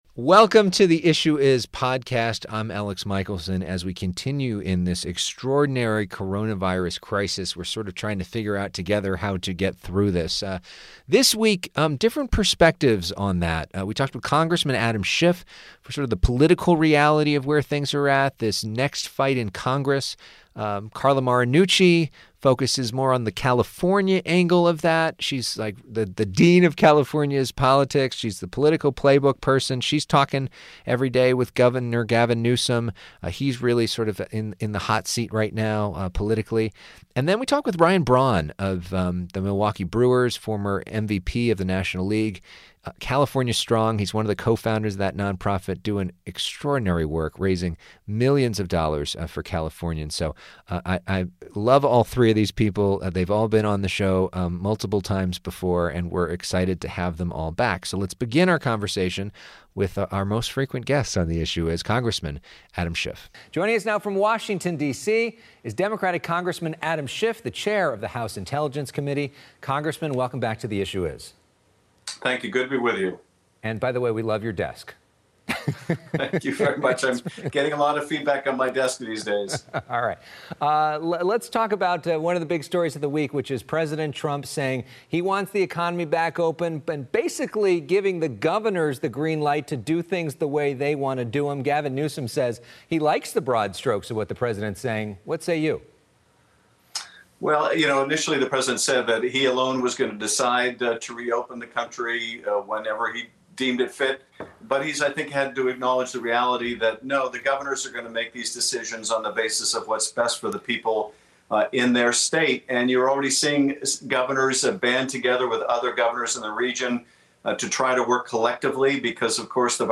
broadcast from FOX 11 Studios in Los Angeles.